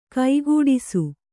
♪ kaigūḍisu]